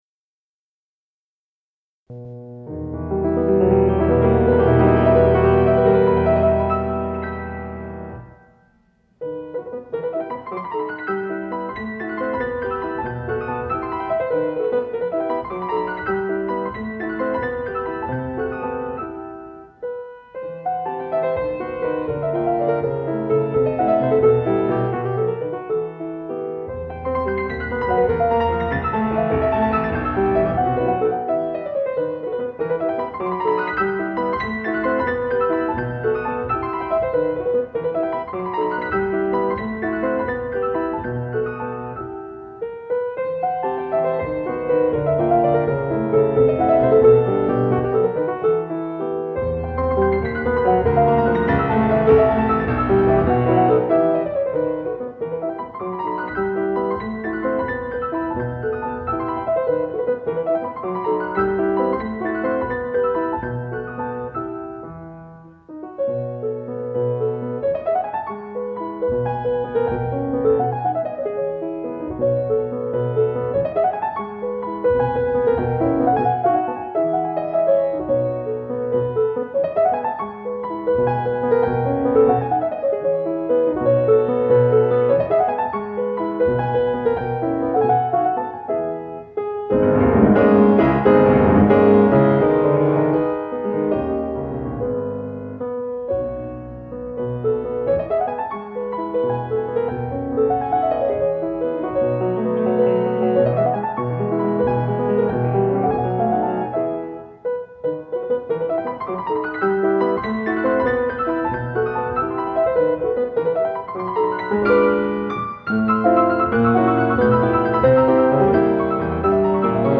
Шопен Вальс ми минор